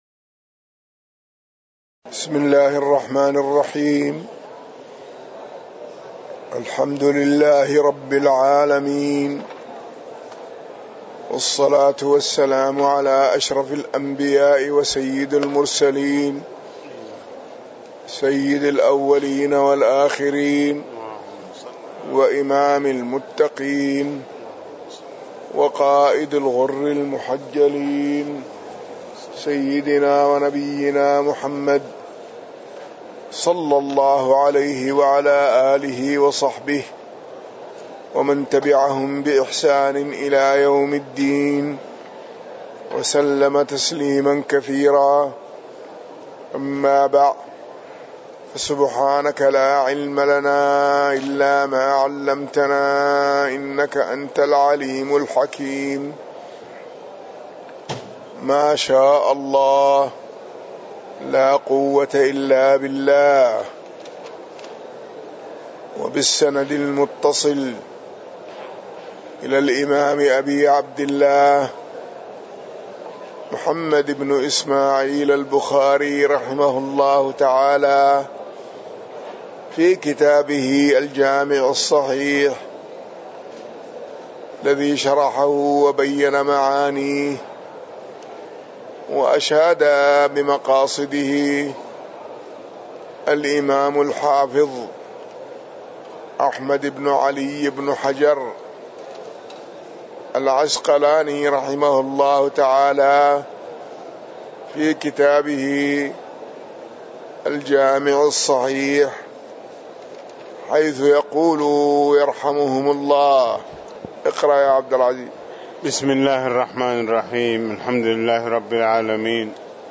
تاريخ النشر ١٨ ذو القعدة ١٤٣٩ هـ المكان: المسجد النبوي الشيخ